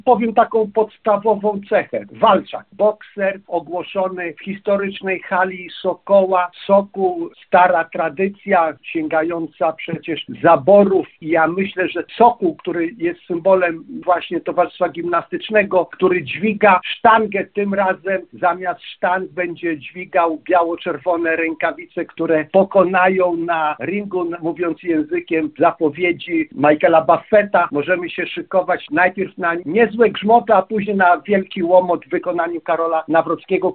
– Jestem bardzo zadowolony z tej kandydatury – mówi lubelski poseł z ramienia Prawa i Sprawiedliwości Sławomir Skwarek (na zdj.).